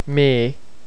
e :